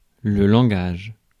Ääntäminen
France: IPA: [lɑ̃.ɡaʒ]